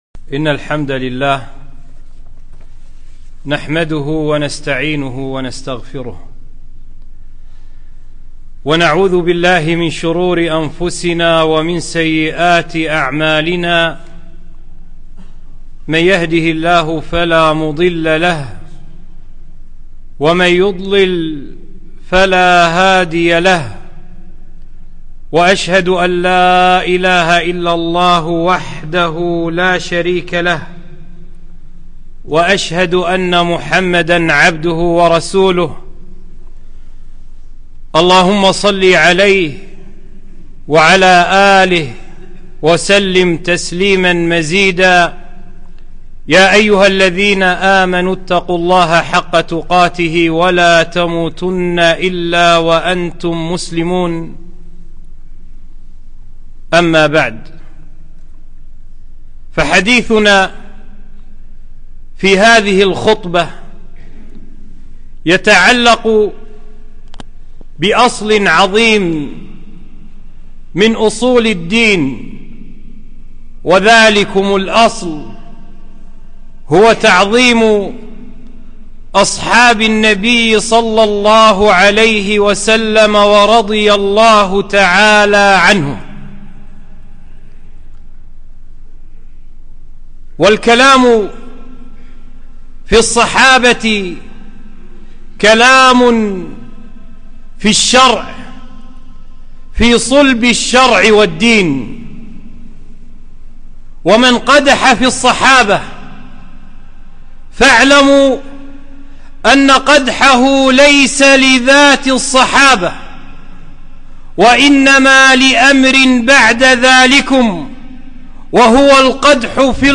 خطبة - بعض فضائل الصَّحب الكرام، وما على المسلم تجاههم